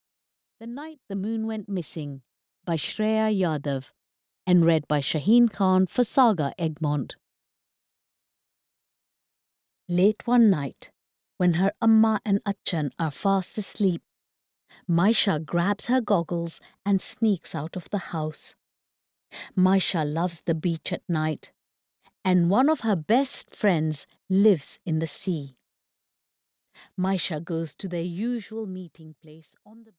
The Night the Moon Went Missing (EN) audiokniha
Ukázka z knihy